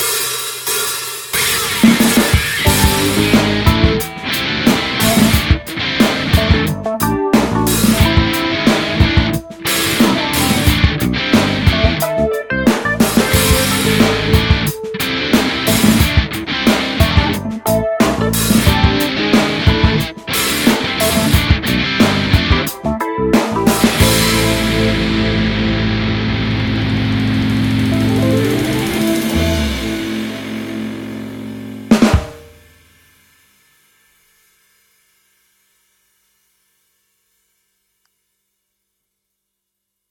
たまにはロックっぽいのを
今回はStudio Devil Virtual Guitar Ampに決定。
試しにロックっぽいのを録音してみました（
しかし電気ギターは爆音で鳴らしてるだけでストレス解消になってよいですね。
rock_or_roll.mp3